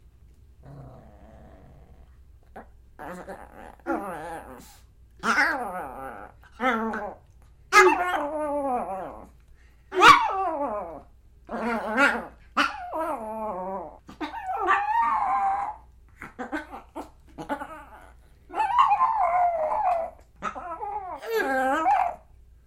На этой странице собраны разнообразные звуки, связанные с померанскими шпицами: от звонкого лая щенков до довольного поскуливания.
Померанский шпиц издает необычный звук